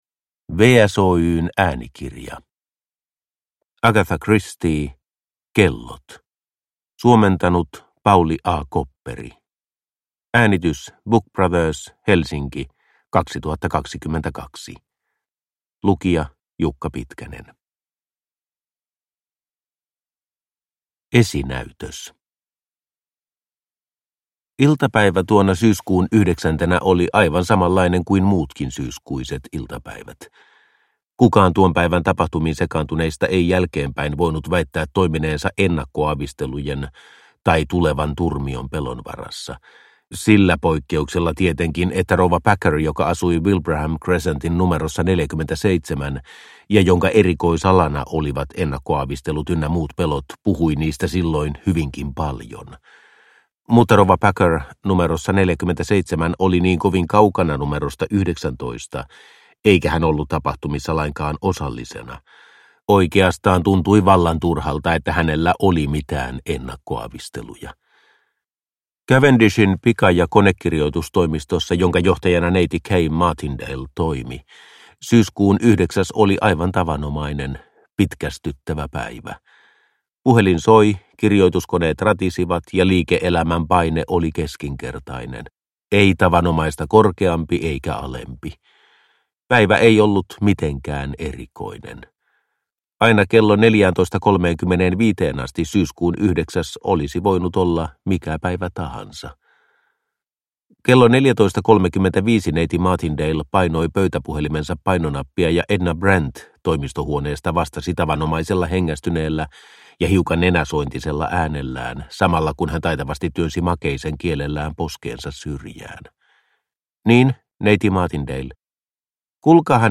Kellot – Ljudbok – Laddas ner